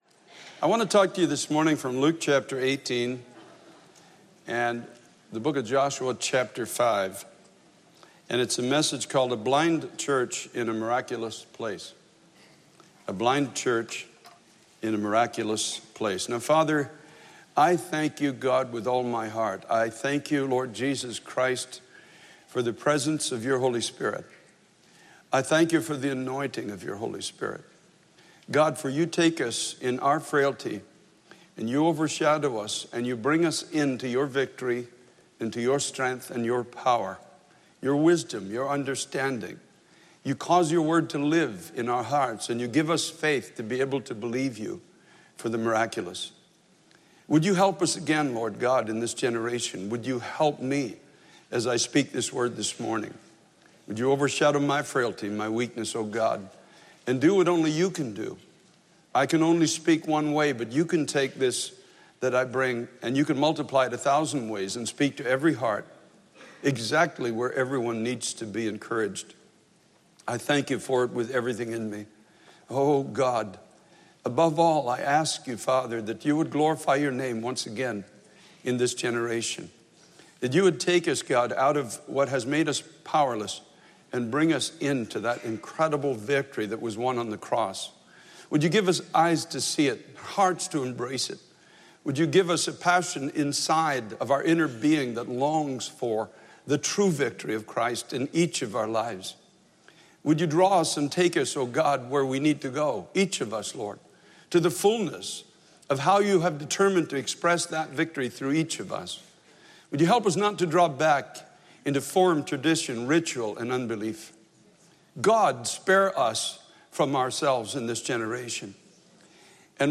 A Blind Church In A Miraculous Place | Times Square Church Sermons